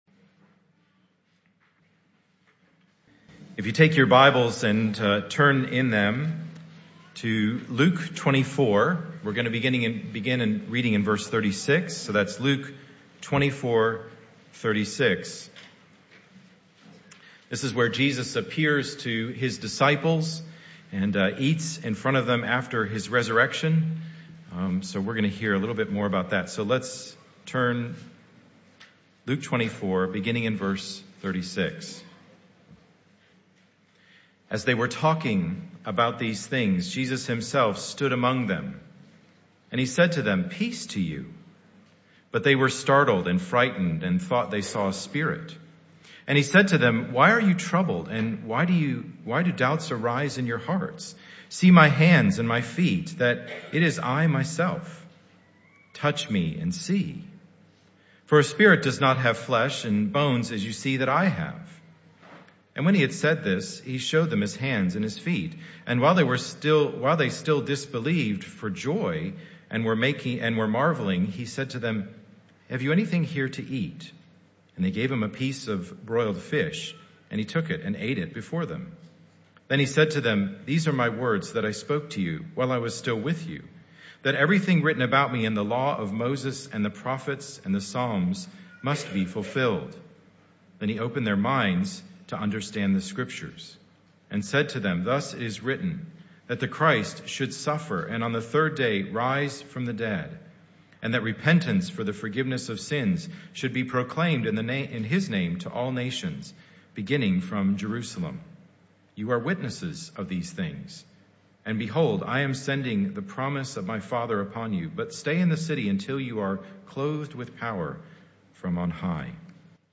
This talk was part of the AM Service series entitled Vine Project Themes (Talk 3 of 5).
Service Type: Morning Service